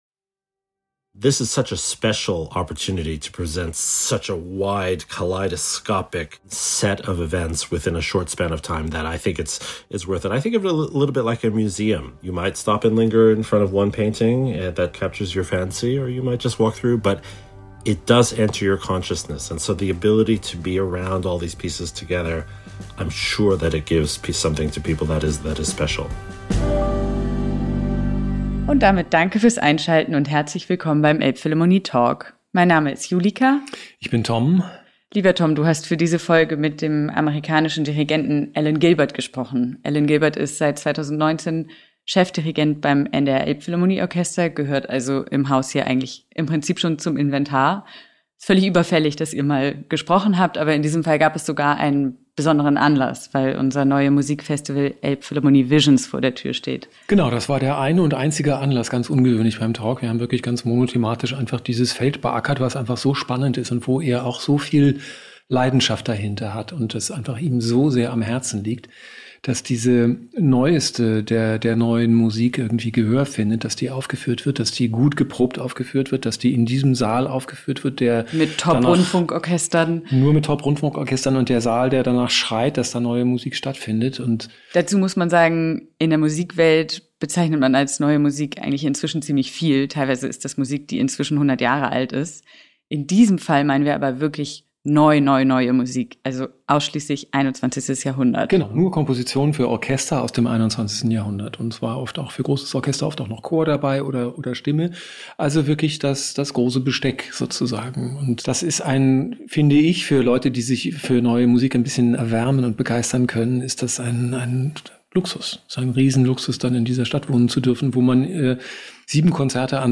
So klingt die Gegenwart: »Elbphilharmonie VISIONS« 2025 - Elbphilharmonie Talk mit Alan Gilbert